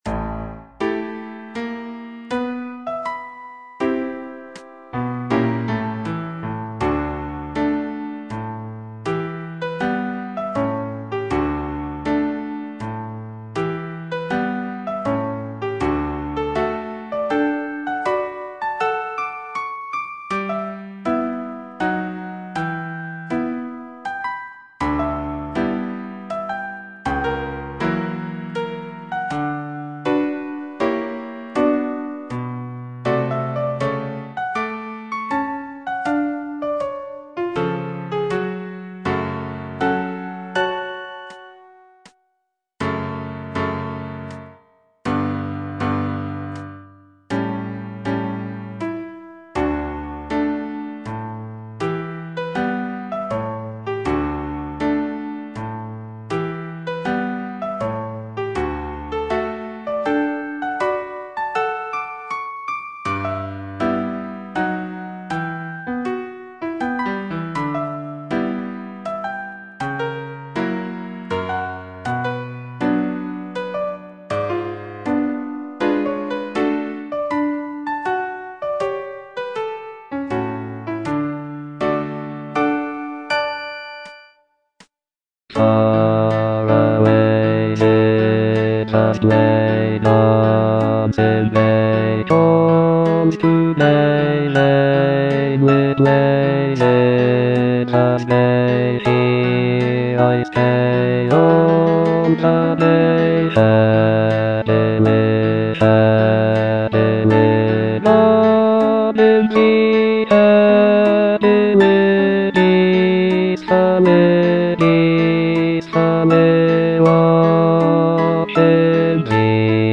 E. ELGAR - FROM THE BAVARIAN HIGHLANDS Lullaby (bass II) (Voice with metronome) Ads stop: auto-stop